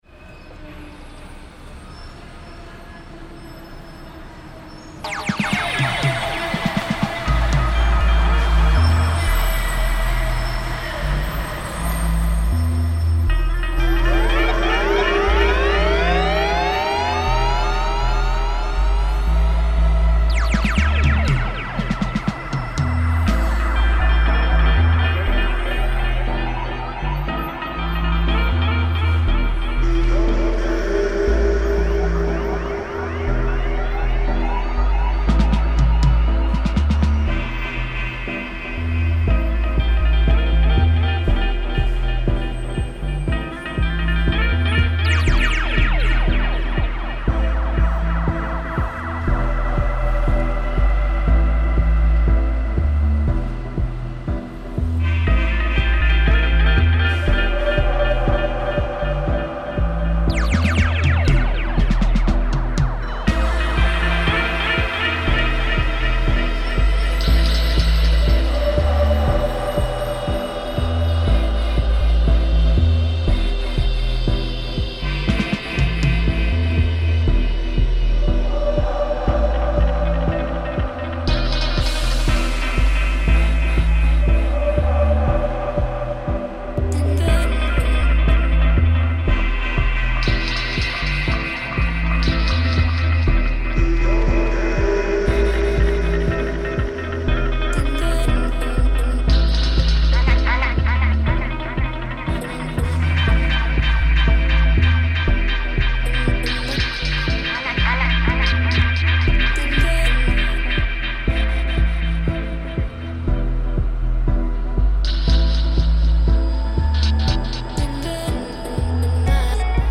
Protest in Bogotá reimagined